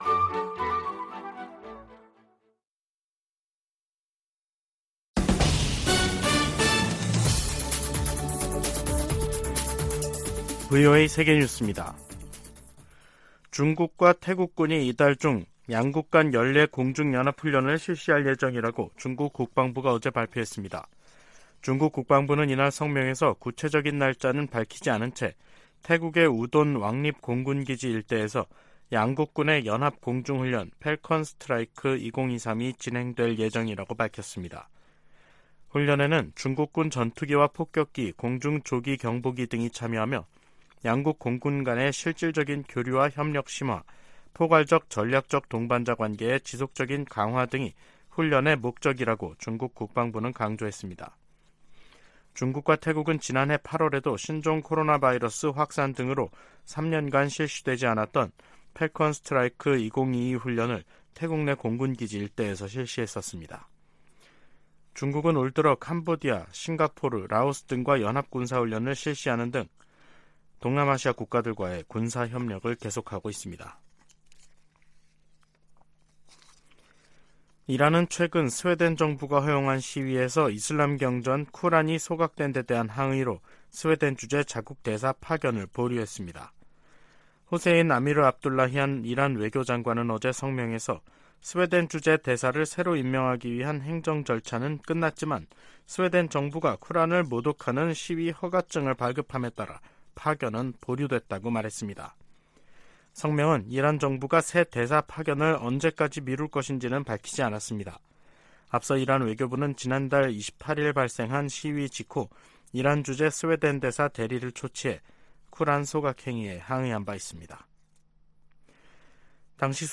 VOA 한국어 간판 뉴스 프로그램 '뉴스 투데이', 2023년 7월 3일 2부 방송입니다. 최근 미국 의회에서는 본토와 역내 미사일 방어망을 강화하려는 움직임이 나타나고 있습니다.